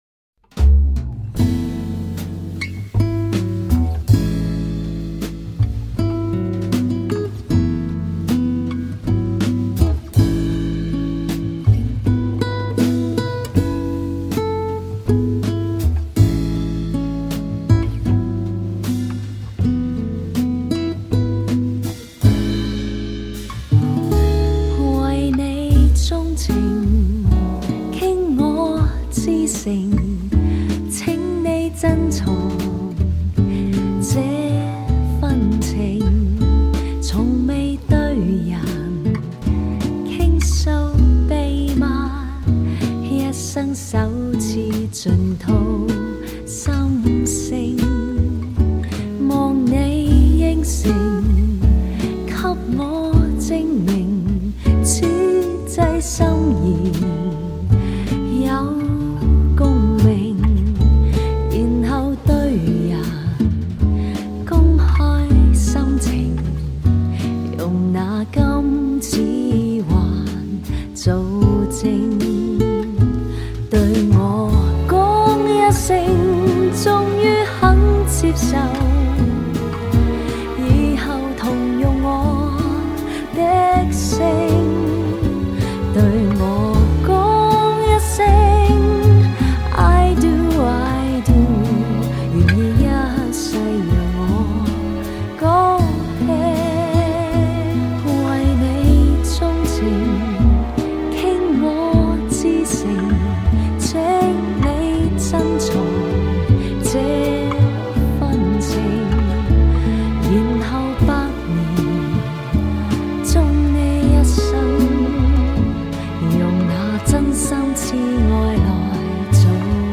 音色更接近模拟(Analogue)声效
强劲动态音效中横溢出细致韵味
歌曲全部被改编至轻爵士乐形式，有高格调，舒服怡人。
录音上乘，听得令人舒畅满意，确是种享受。